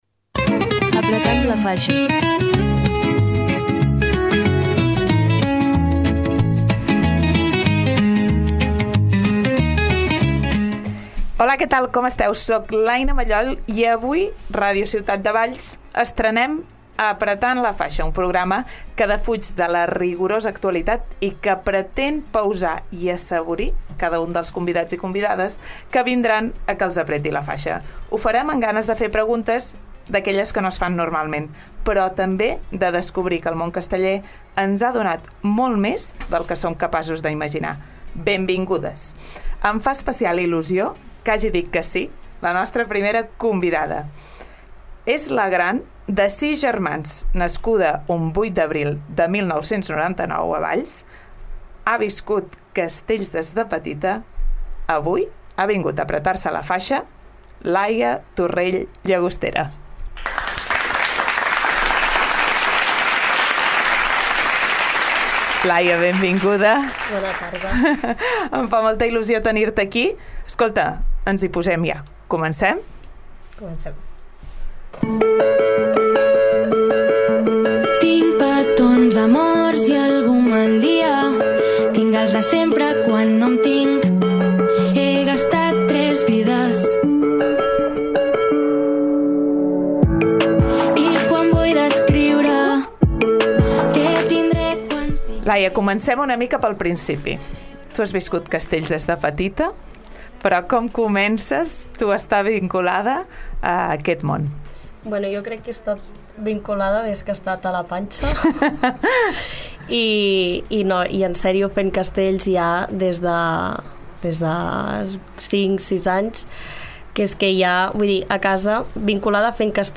Arrenquem l’espai amb una convidada molt especial.